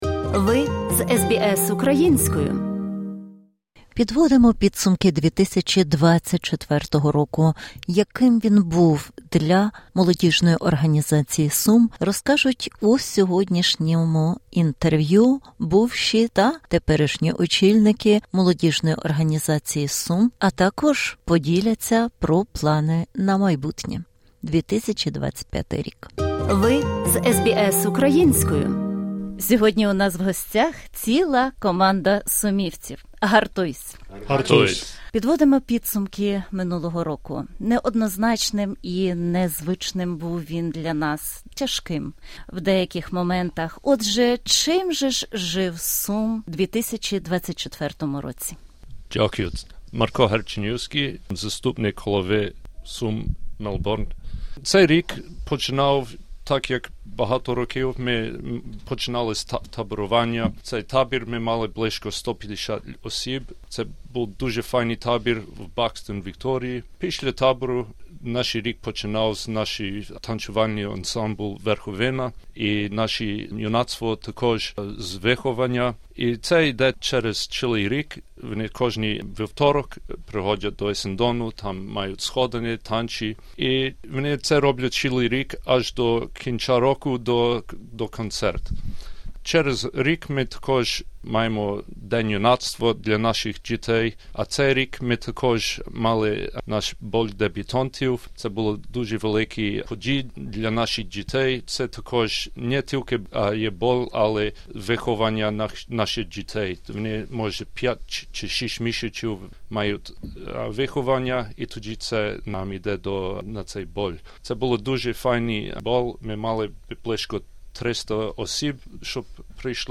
Це інтерв’ю висвітлює основні досягнення та виклики, з якими зіткнулася Спілка Української Молоді (СУМ) за останній рік.